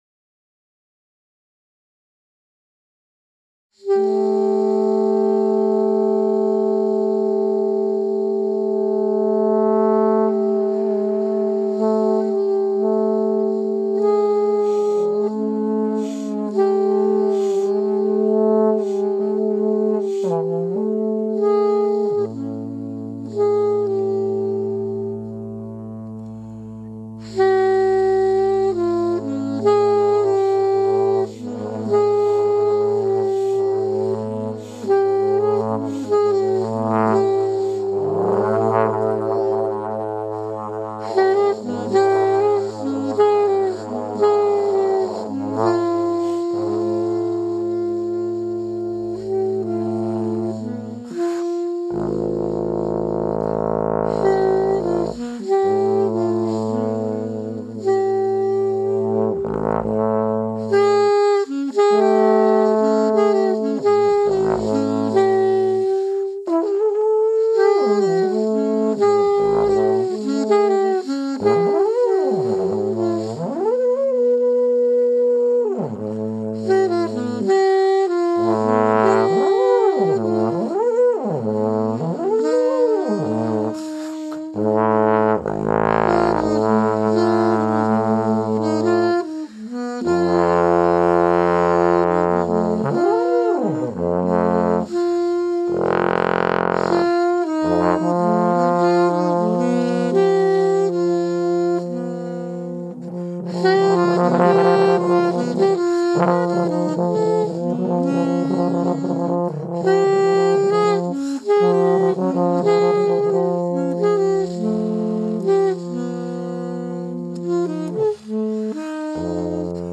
Zwischen zwei kürzeren Improvisationen sprechen wir in dieser Folge über Konzert- und Theaterbesuche oder dass wir eben zu wenig an solche Aufführungen gehen und geben weitere Einblicke in unser Musikerdasein.